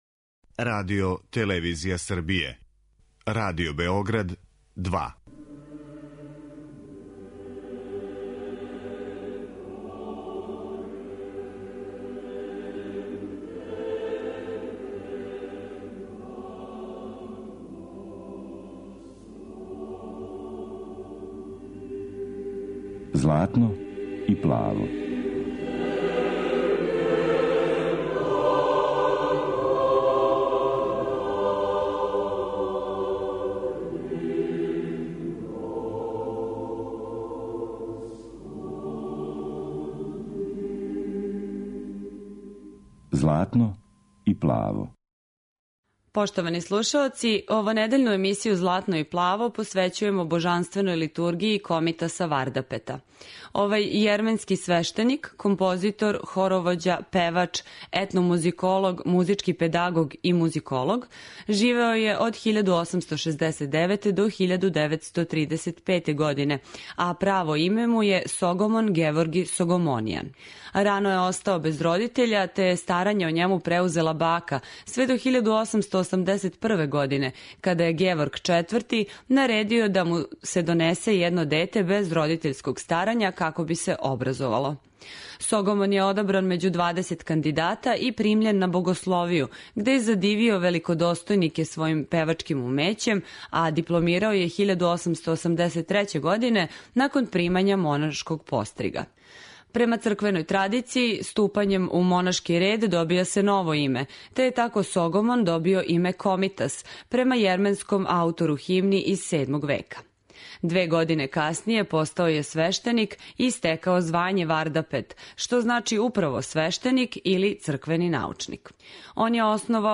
Емисија православне духовне музике